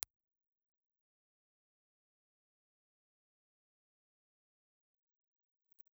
Condenser
Cardioid
Impulse Response File:
Syncron IR file